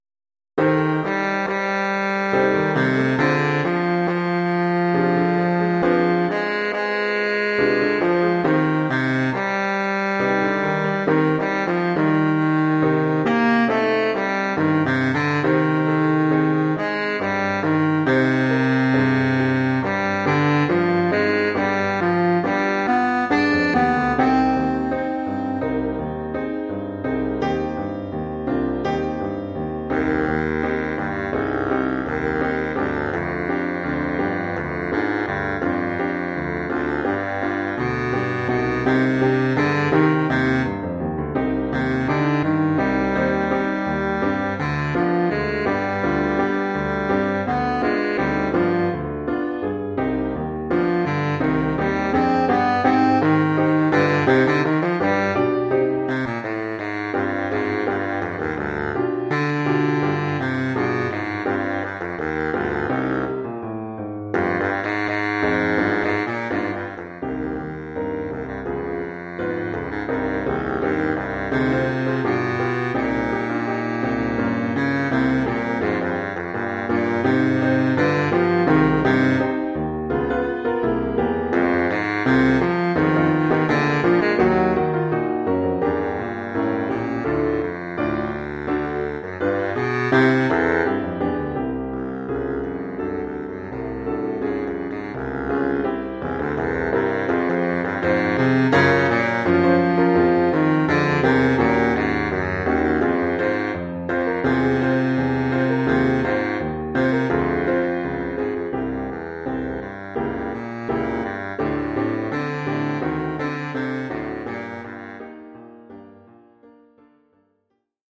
Oeuvre pour saxophone baryton et piano.